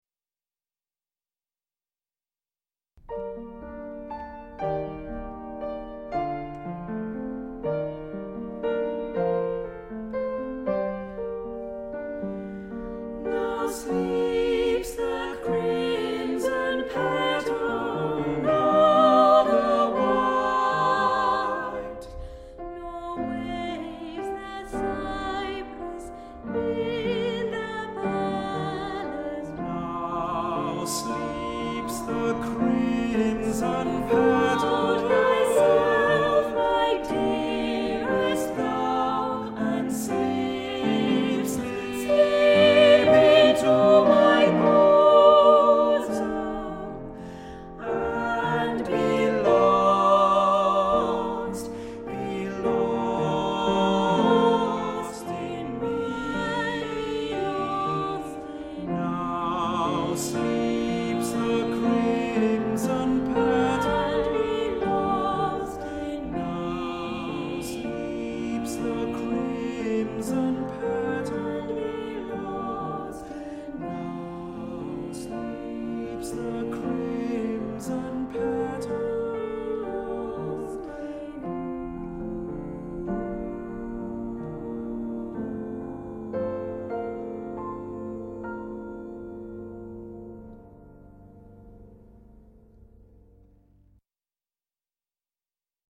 Voicing: Choir